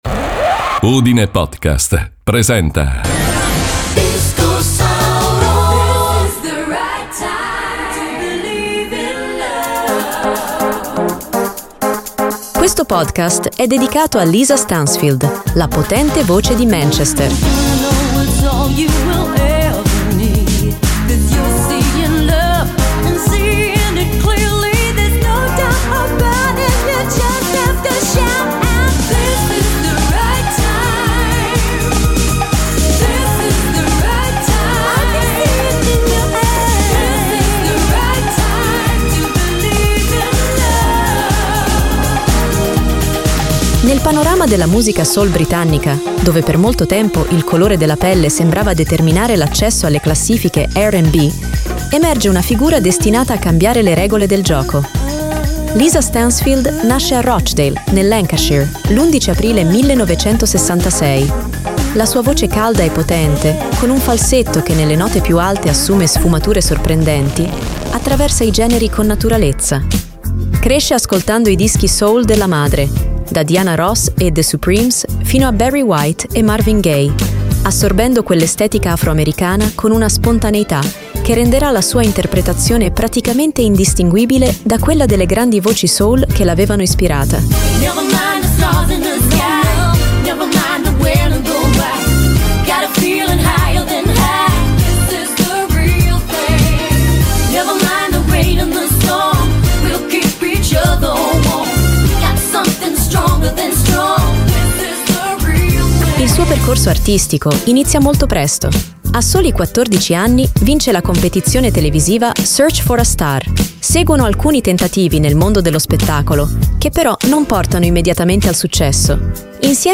This is the right time (1989)
All around the world (1989)